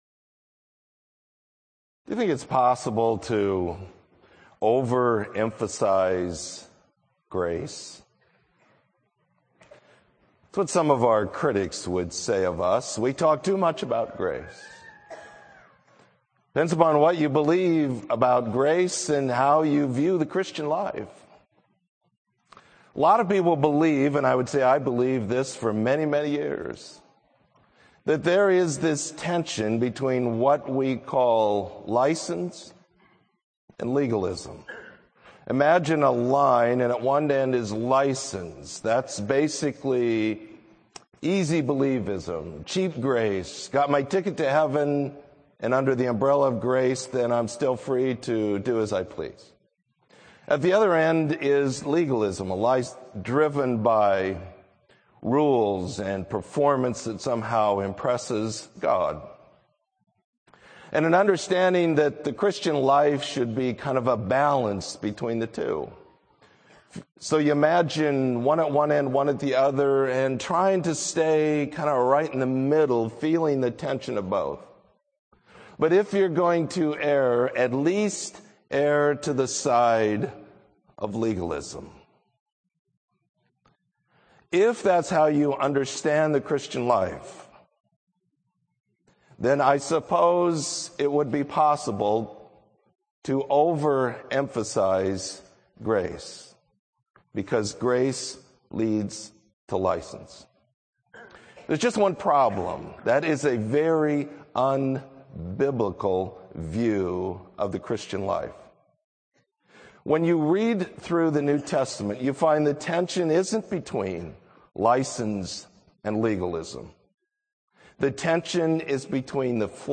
Sermon: License, Legalism or Grace